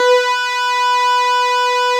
Added synth instrument
snes_synth_059.wav